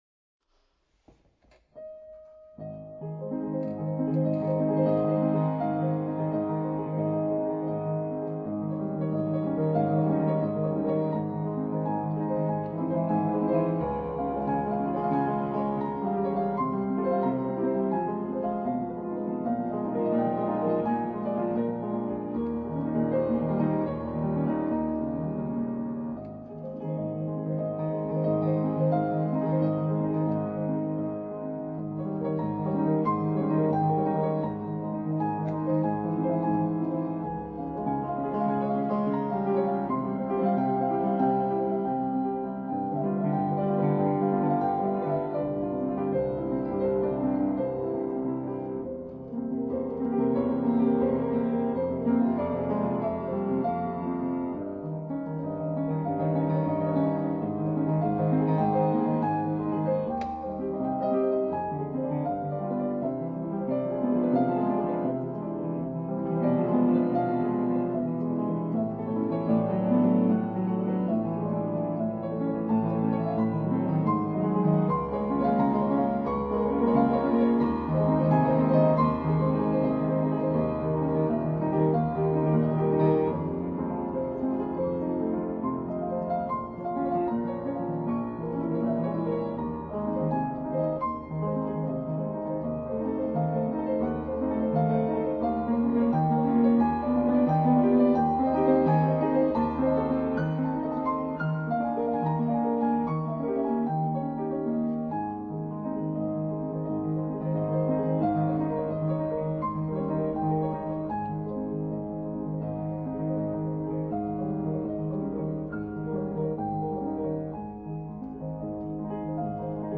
(cache) ピアノコンサート１
終盤指もつれてます。内声だけでなく旋律もかすれぎみ（汗）